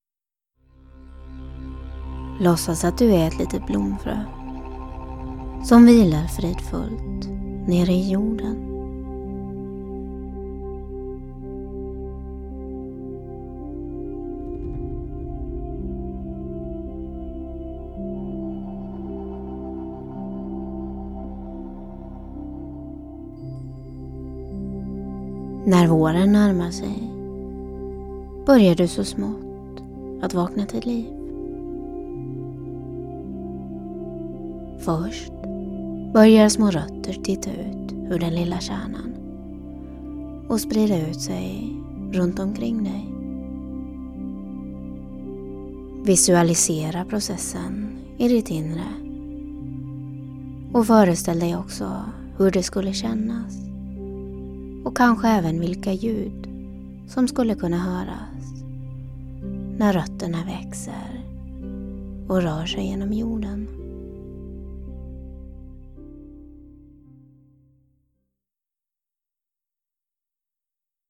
I dagdrömsmeditationen En blomma får liv får du använda dig av din fantasi när du får låtsas att du är ett blomfrö som växer upp och blir en fullt utslagen blomma. Meditationen är mycket lugnande och är bra att använda för avslappning och att hantera stress.
Bakgrundsmusik:
En-blomma-får-liv.-En-guidad-Meditation.-MP3-Sample.mp3